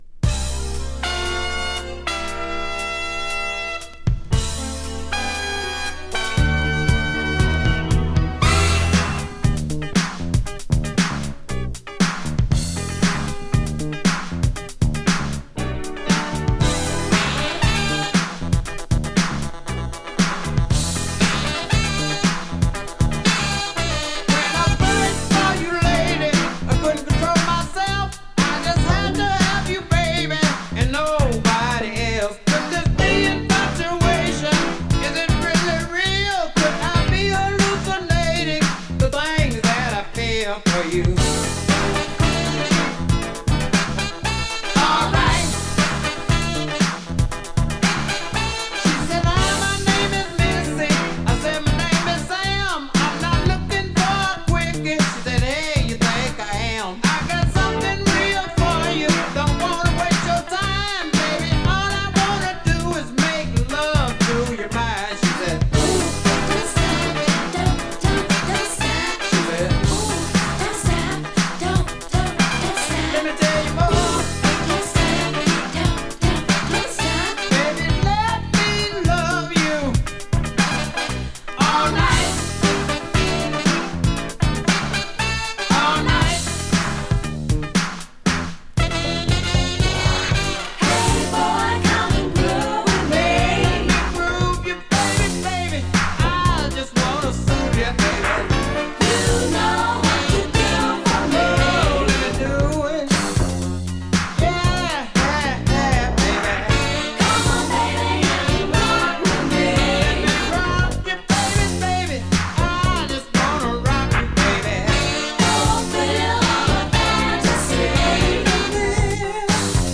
Indie Soul from California！
【SOUL】【FUNK】